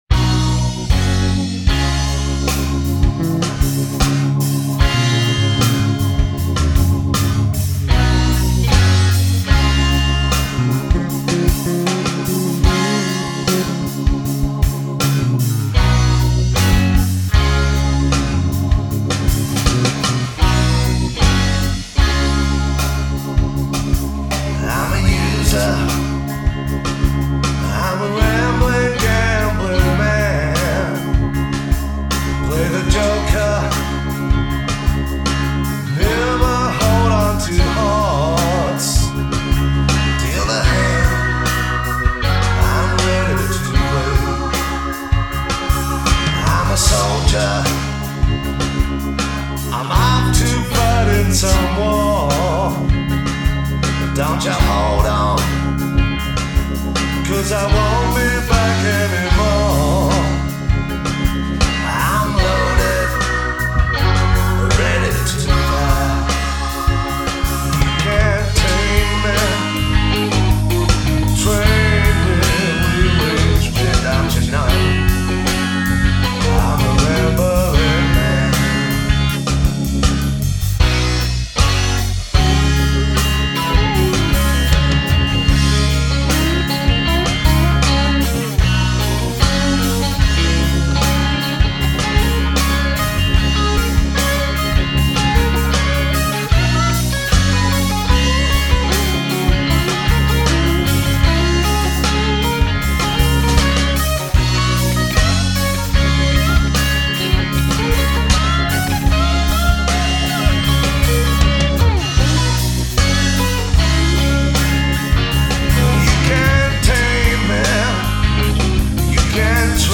Americana